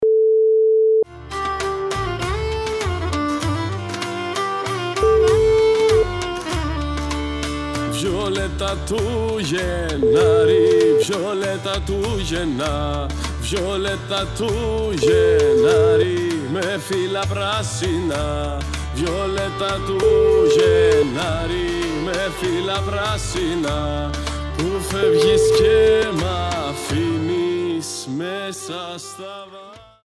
Νησιώτικα